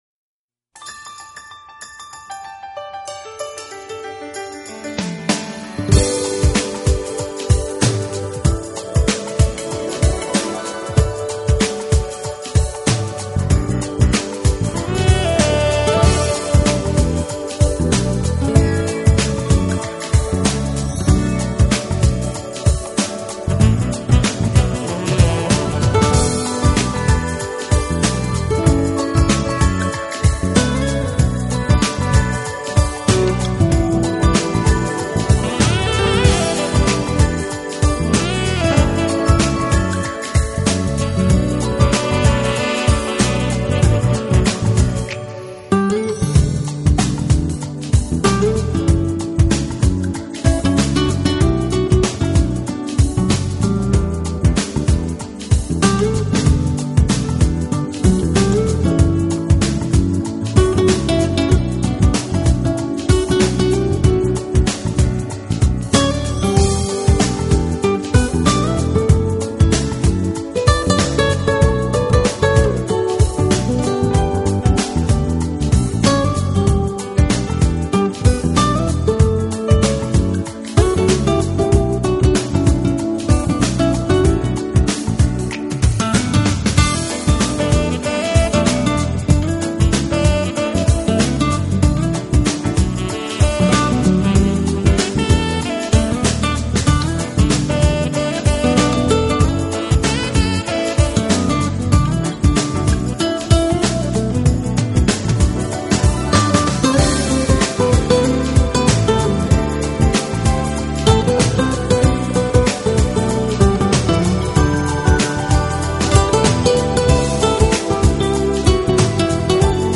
音乐类型: Smooth Jazz
vocals, guitar, accordion, keyboards, Mini Moog
soprano & tenor saxophones, synthesizer